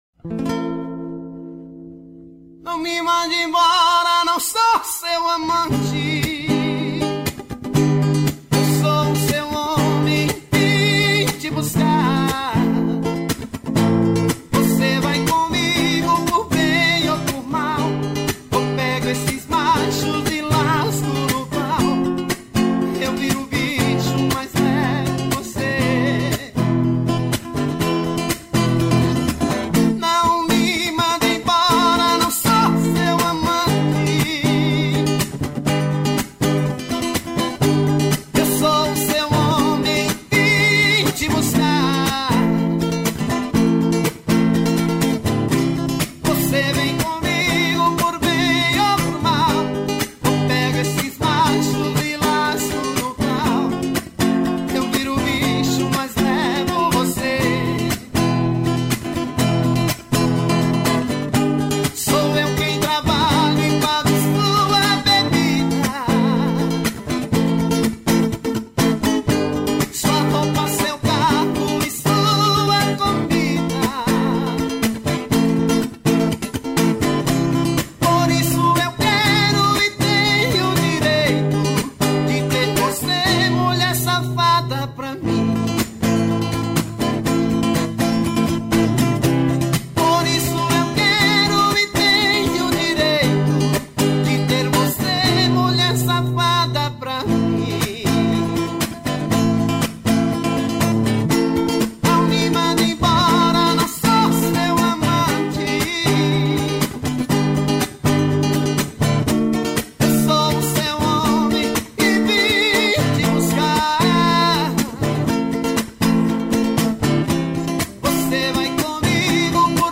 ao vivo voz e violão